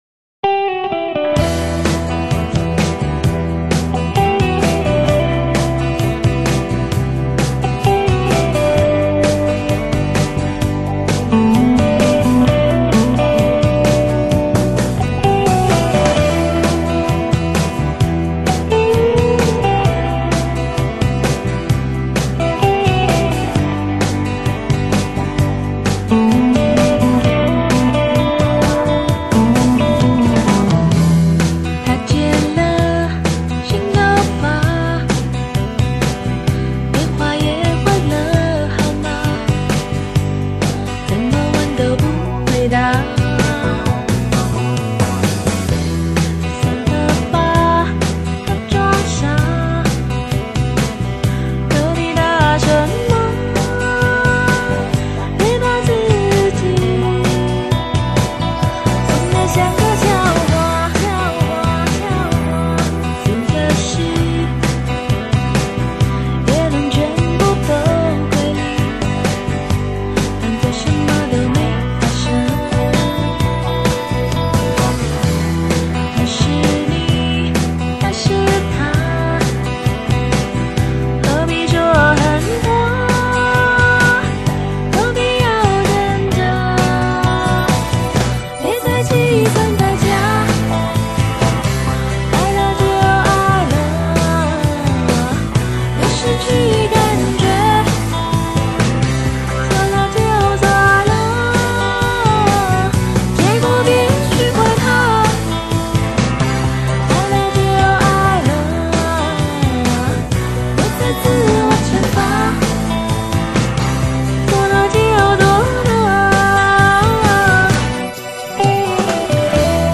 音太高，累死偶了